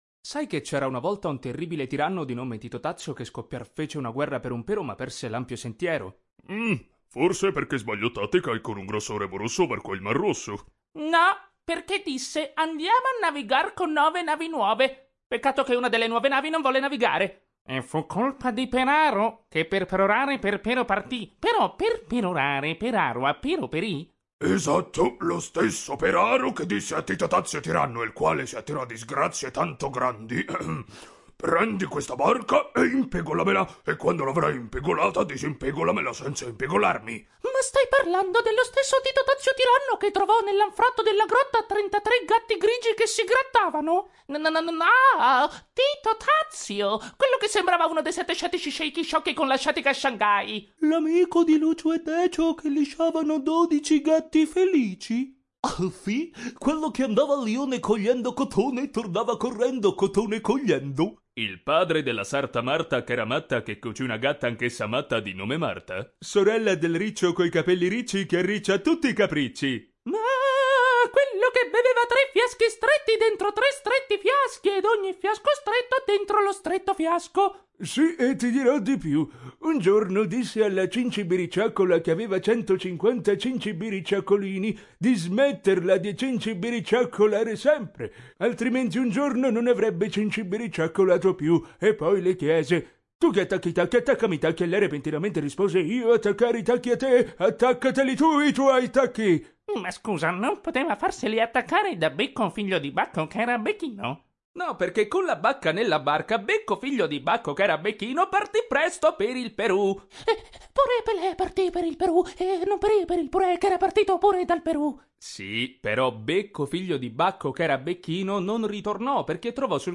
Animation
My voice it’s young, warm, and deep.
It also can be funny and speedy, or smooth, sexy, crazy, sad an so on.
RODE NT1A
Young Adult